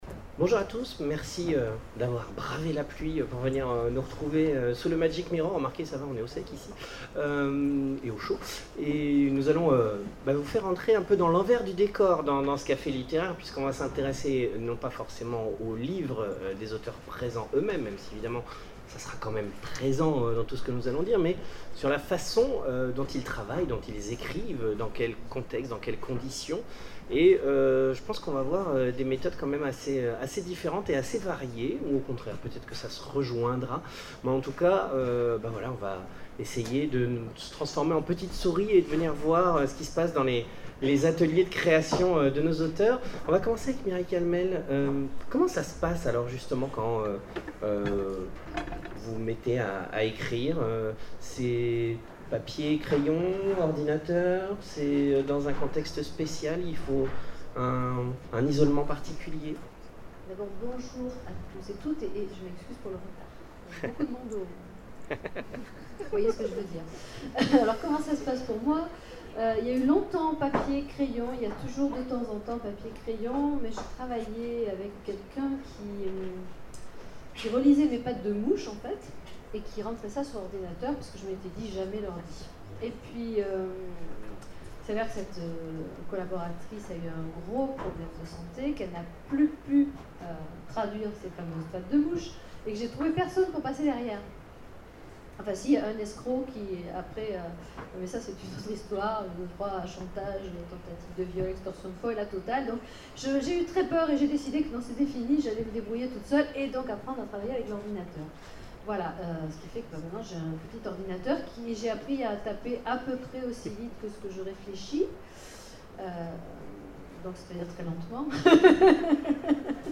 Imaginales 2012 : Conférence Comment écrivez-vous ?